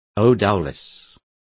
Complete with pronunciation of the translation of odourless.